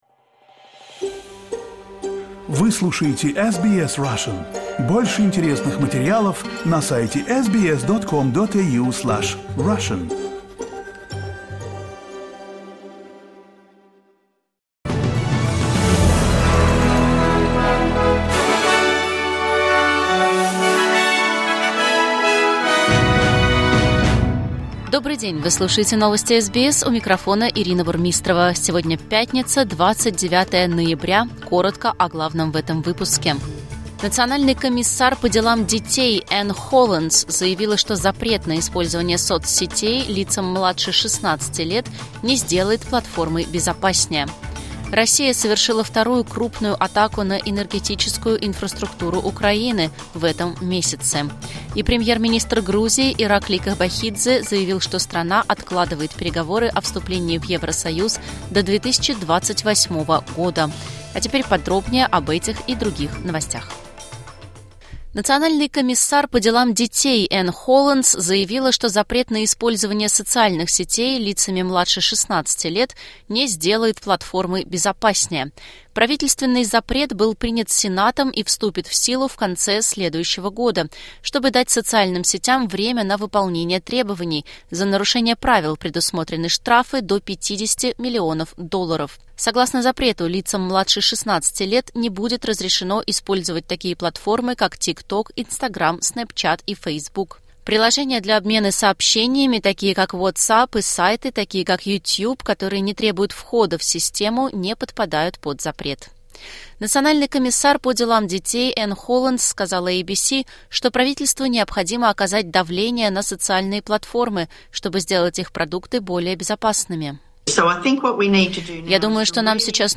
Новости SBS на русском языке — 29.11.2024